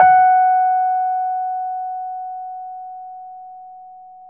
RHODES-F#4.wav